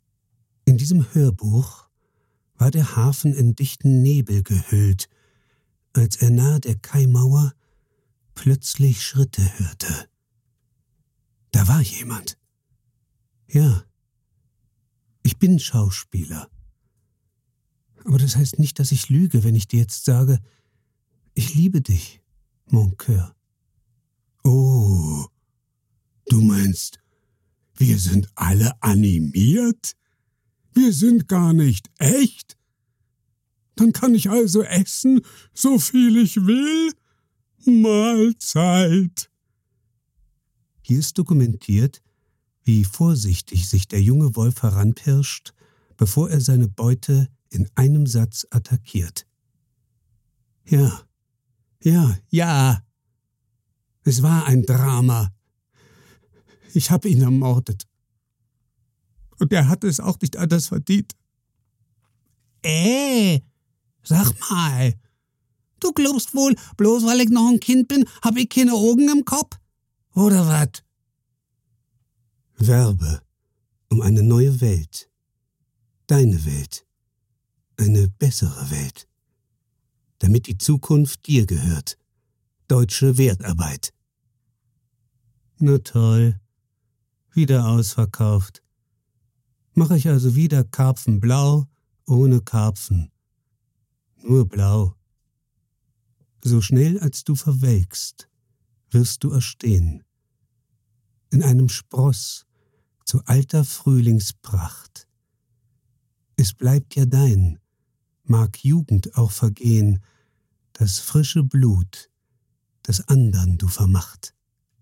Gelernter Schauspieler. Glaubwürdig, ausdrucksstark, überzeugend, stimmlich variabel. Dialektfreie Mittellage, weich & samtig.
Sprechprobe: Sonstiges (Muttersprache):
Trained german actor. Credible, expressive, convincing, vocally variable. Dialect-free mid-range, soft & velvety.